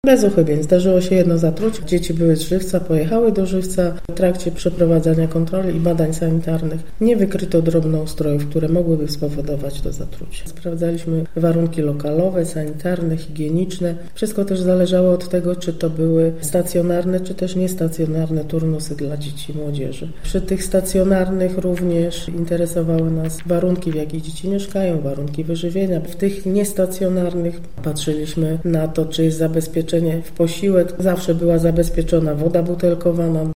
Mówi Dorota Baranowska, Państwowy Powiatowy Inspektor Sanitarny w Zielonej Górze: